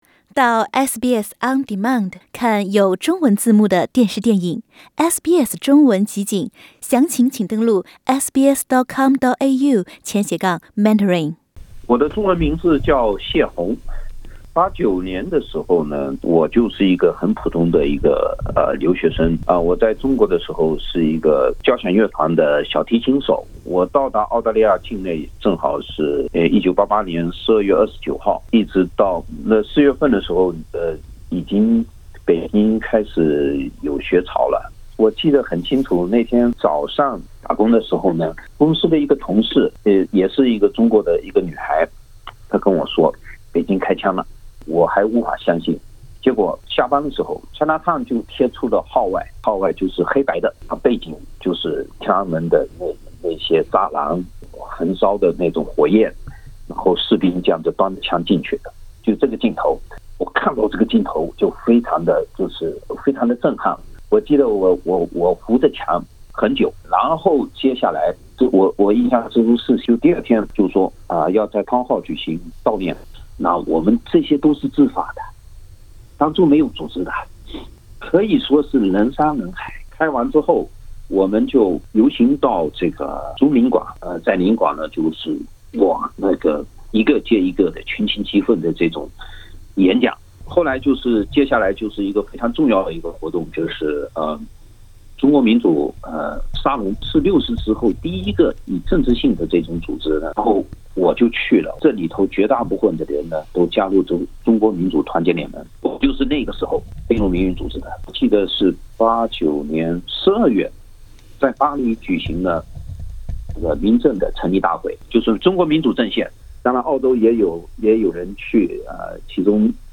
更多内容，请点击收听音频采访。